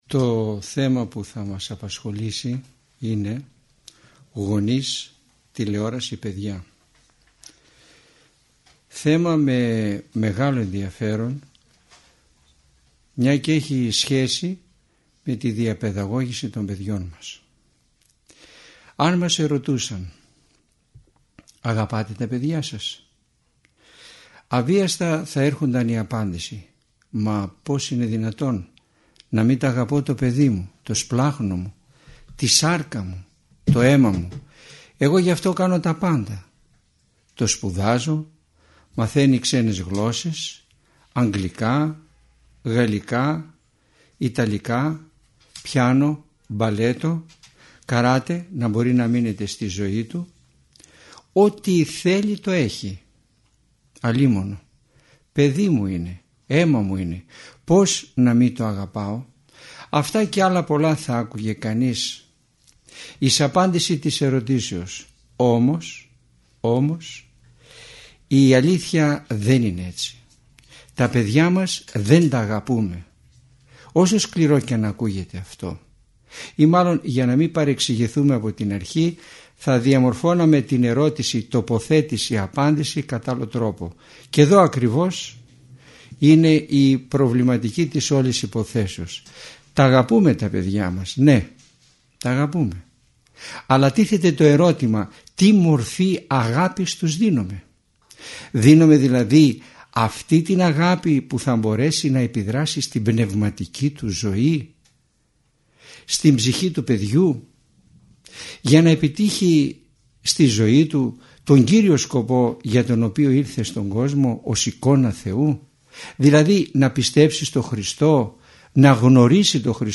Γονείς, τηλεόραση, παιδιά – ηχογραφημένη ομιλία